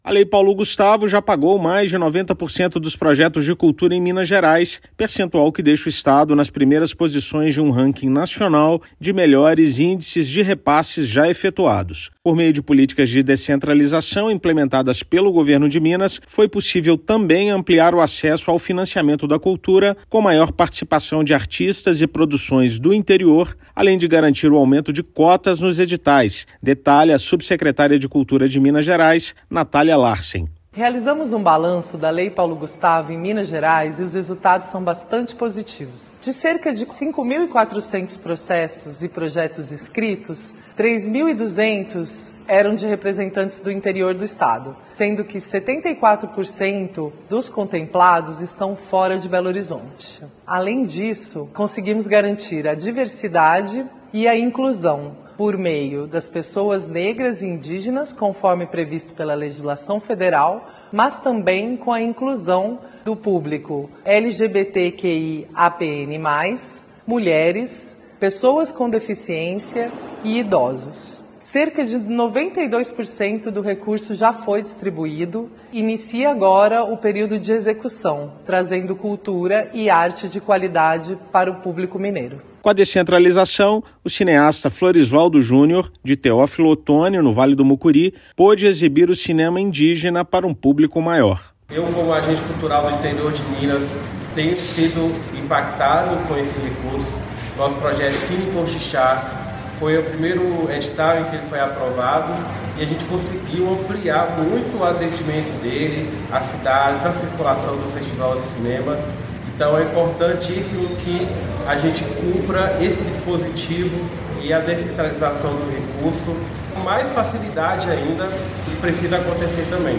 [RÁDIO] Minas Gerais realiza mais de 90% dos projetos culturais via Lei Paulo Gustavo, com participação histórica do interior
Governo do Estado fomenta políticas de descentralização, ampliação do acesso ao mecanismo e aumento do percentual de cotas em dez editais. Ouça matéria de rádio.